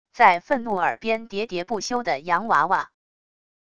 在愤怒耳边喋喋不休的洋娃娃wav音频